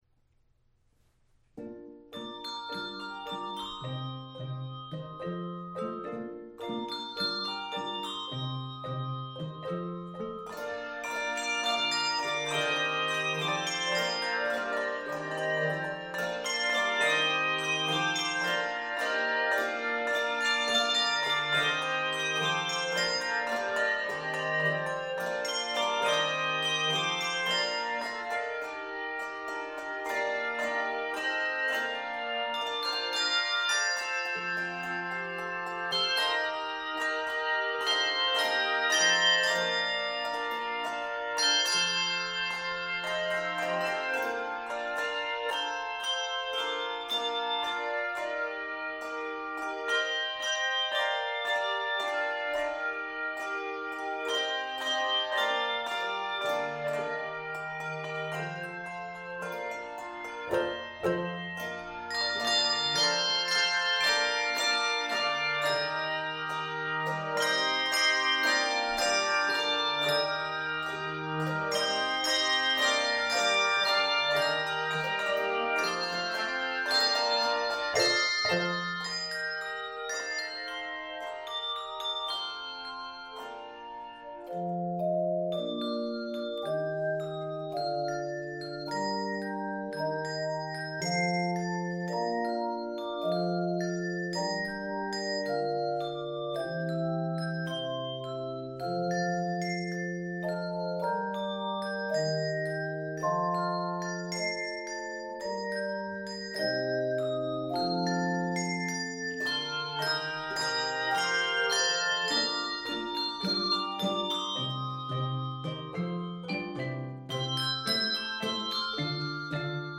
With exciting rhythmic vitality
Keys of Bb Major and C Major.
Octaves: 3-5